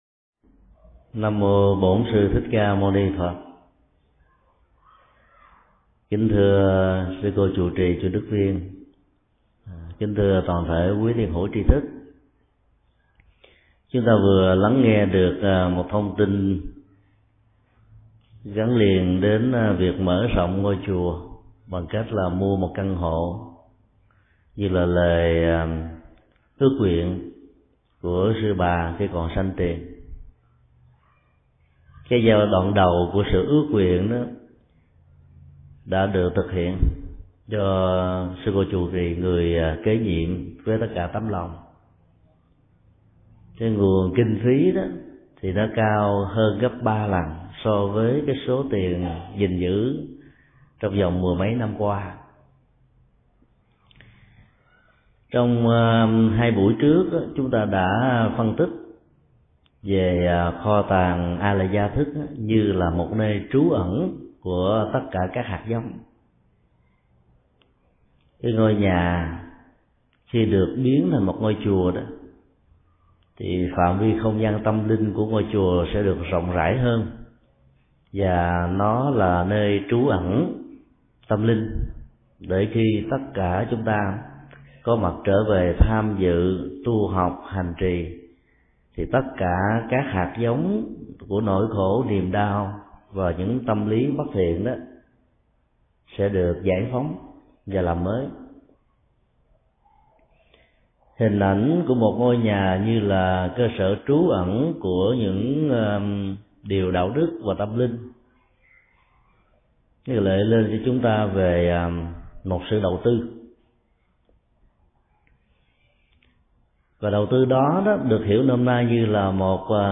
Mp3 Thuyết Giảng Duy thức 4: Thức Mạt-na